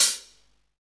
HAT FUZN 0FL.wav